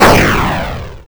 explosion_2.wav